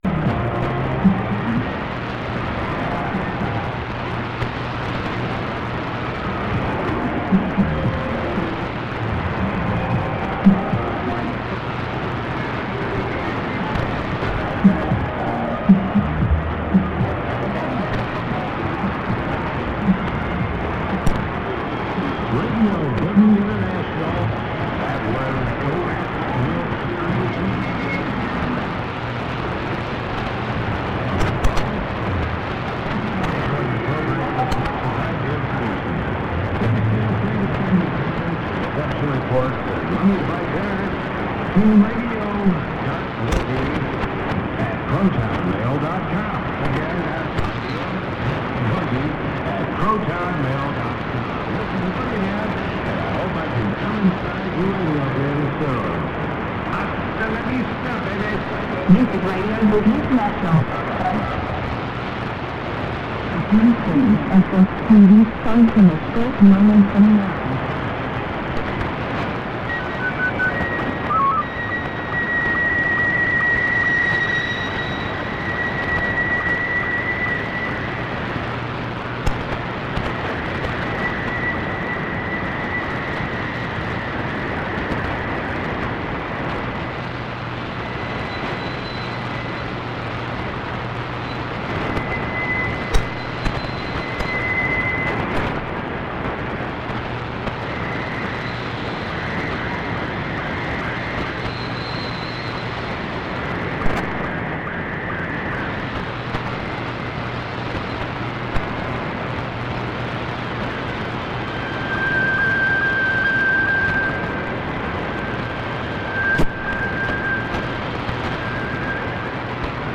Radio Boogie - RX Recording